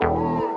TGOD All Night Pluck.wav